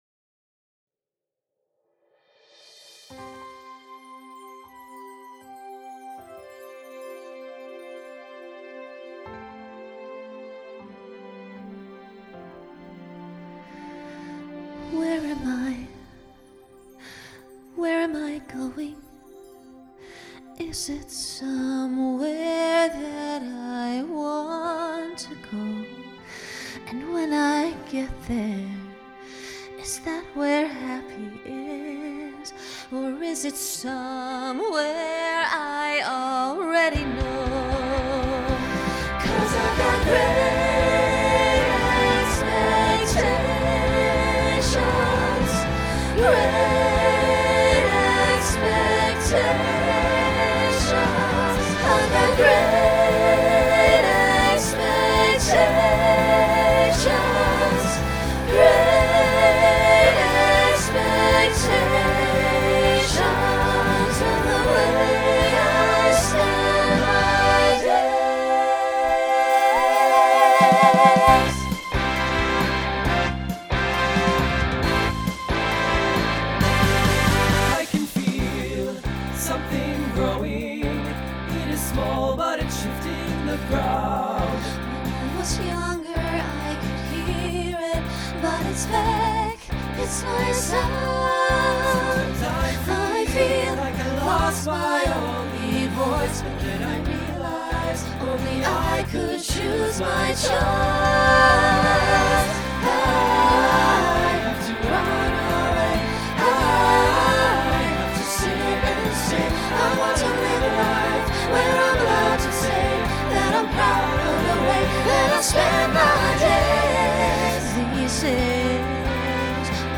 Genre Broadway/Film Instrumental combo
Solo Feature Voicing SATB